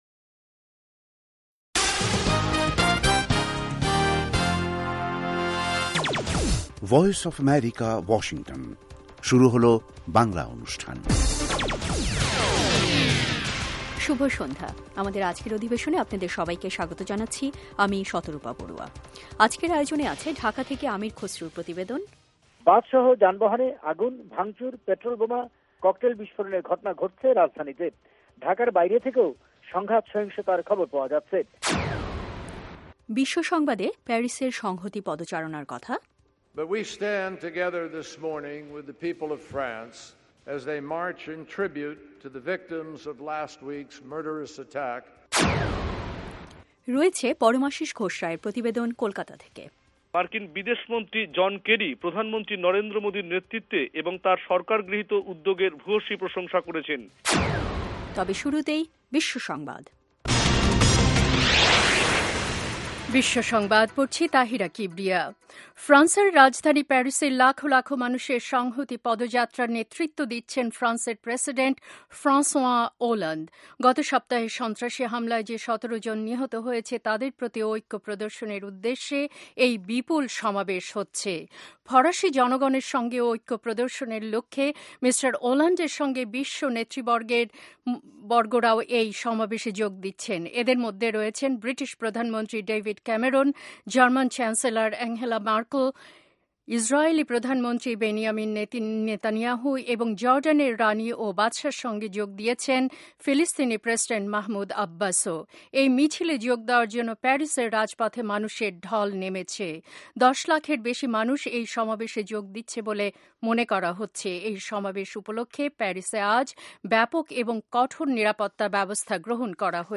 অনুষ্ঠানের শুরুতেই রয়েছে আন্তর্জাতিক খবরসহ আমাদের ঢাকা এবং কলকাতা সংবাদদাতাদের রিপোর্ট সম্বলিত বিশ্ব সংবাদ, এর পর রয়েছে ওয়ার্ল্ড উইন্ডোতে আন্তর্জাতিক প্রসংগ, বিজ্ঞান জগত, যুব সংবাদ, শ্রোতাদের চিঠি পত্রের জবাবের অনুষ্ঠান মিতালী এবং আমাদের অনুষ্ঠানের শেষ পর্বে রয়েছে যথারীতি সংক্ষিপ্ত সংস্করণে বিশ্ব সংবাদ।